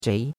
zhei2.mp3